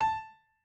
piano6_25.ogg